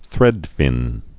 (thrĕdfĭn)